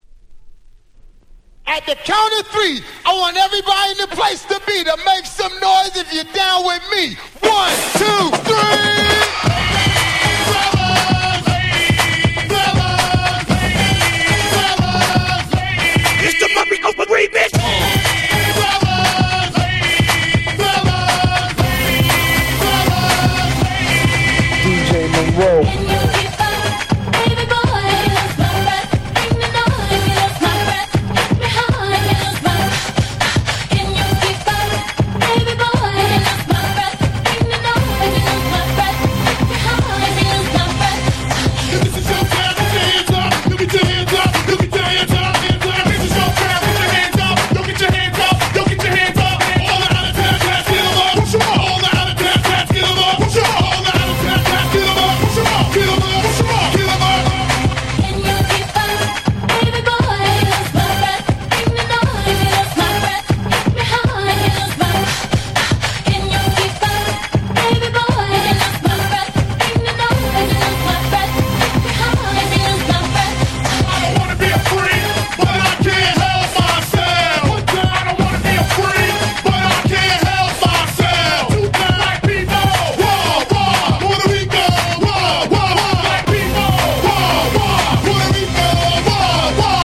04' Nice Remix !!